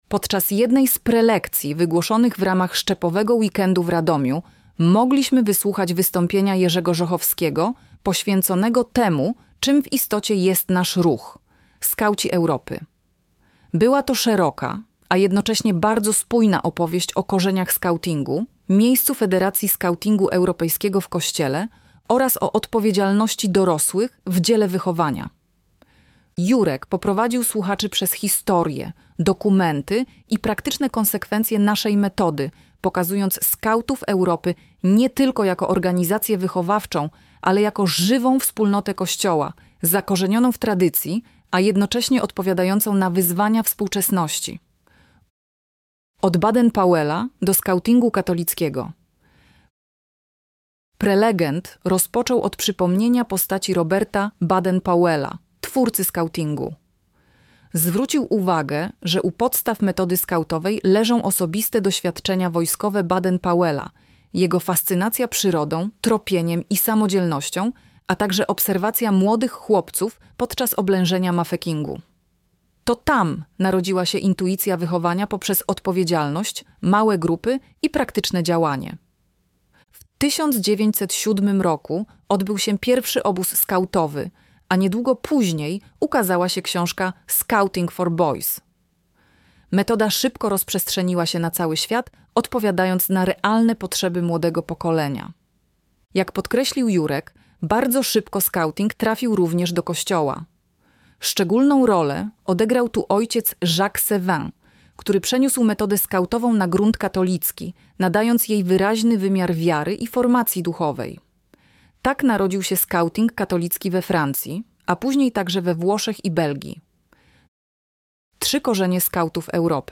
Szczepowy weekend 2025 - Wsparcie, motywacja, rozwój